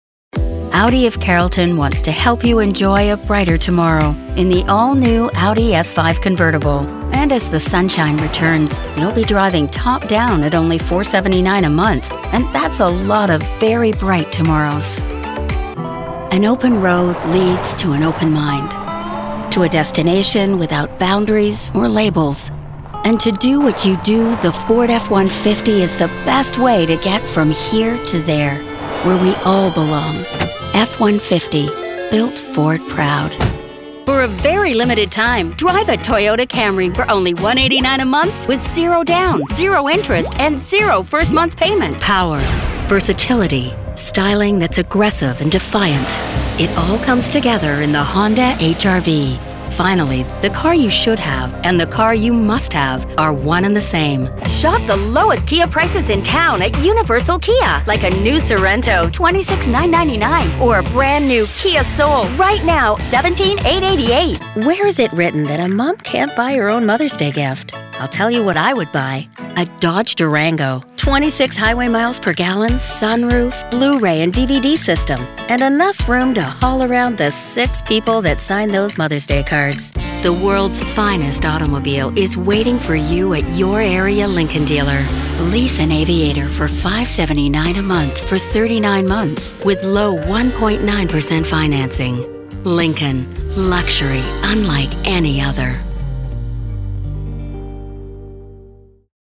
Female
Bright, Confident, Corporate, Friendly, Natural, Posh, Reassuring, Smooth, Soft, Warm, Versatile, Young, Approachable, Authoritative, Conversational, Energetic, Engaging, Upbeat
Canadian (native) neutral North American British RP
Audio equipment: professionally built booth / UR22mkII interface